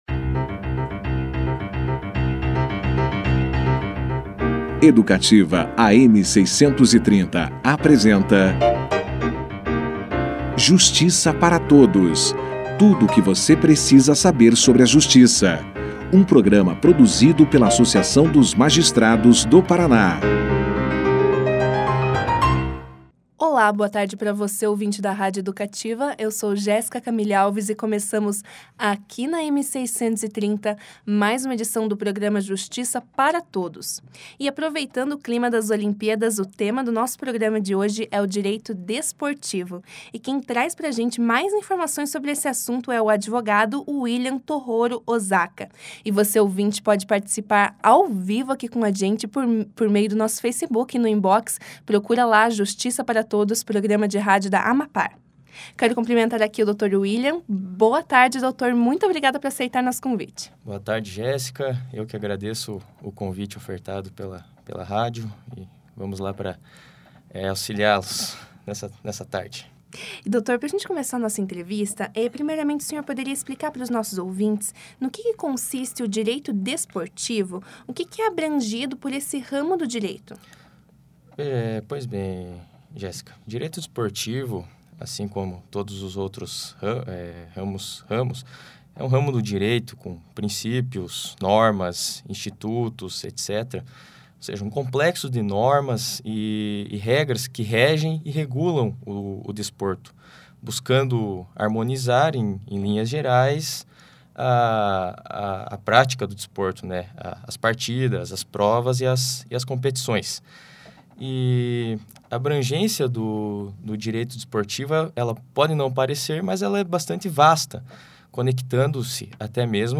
Mais ao final da entrevista